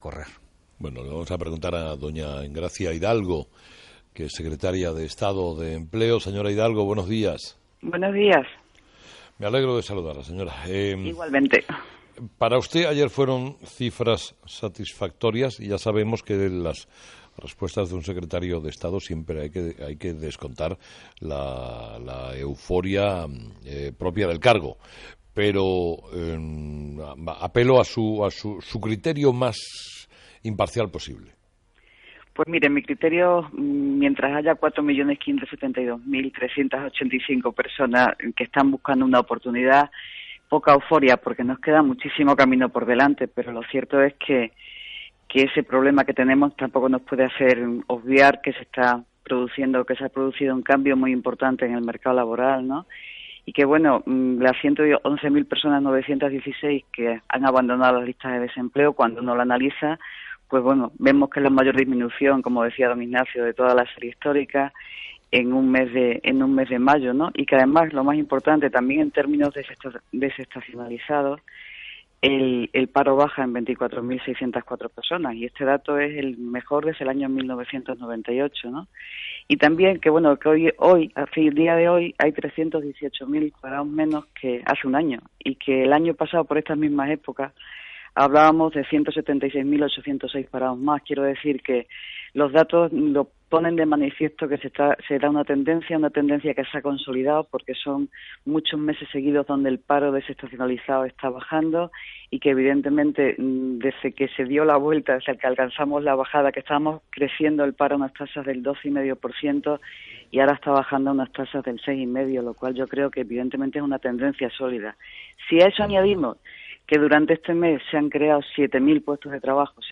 Entrevista a Engracia Hidalgo
La secretaria de Estado de Empleo, Engracia Hidalgo, analiza en Herrera en la onda los datos del paro de mayo y señala que "nos queda mucho camino por hacer pero se ha producido un importante cambio en el mercado laboral".